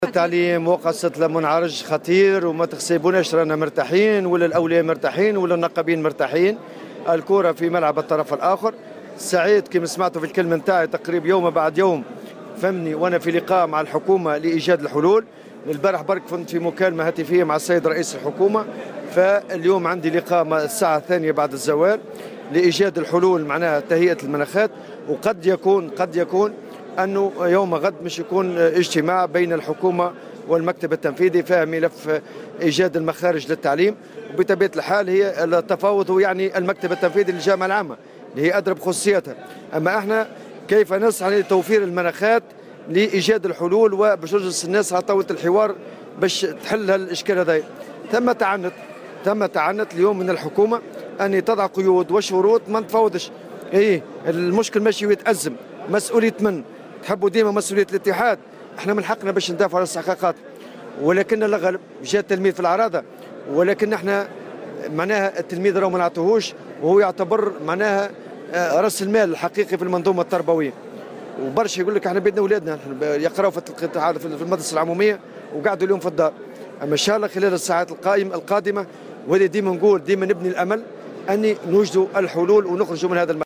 وأضاف الطبوبي في تصريح اليوم لمراسلة "الجوهرة أف أم" على هامش المؤتمر العادي 23 للاتحاد الجهوي للشغل بنابل، أنه من المنتظر أيضا أن يتم عقد اجتماع يوم غد بين الحكومة والمكتب التنفيذي بخصوص هذا الملف وللجلوس مجدّدا على طاولة التفاوض، معبرا عن أمله في التوصل إلى حلول للخروج من هذا المأزق.